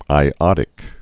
(ī-ŏdĭk)